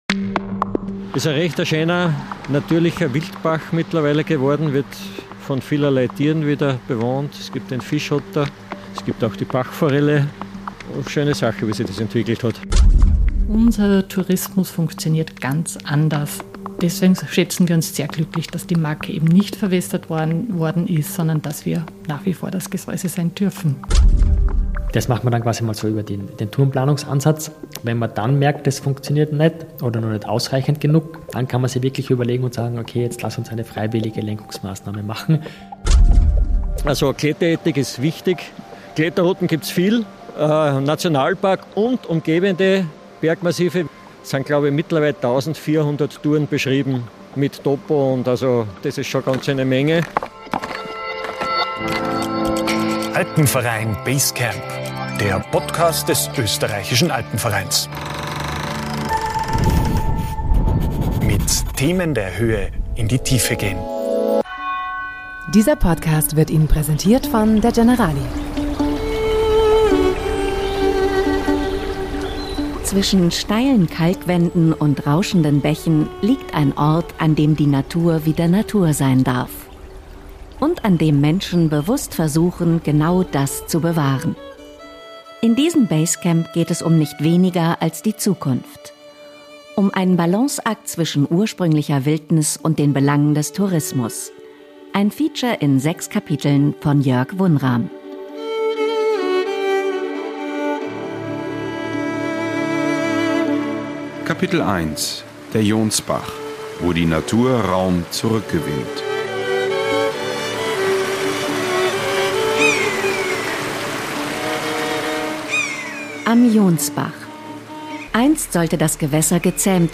Warum gelingt hier der Balanceakt vergleichsweise geräuschlos, wo er anderswo zu erheblichen Konflikten führt? Ein Feature in sechs Kapiteln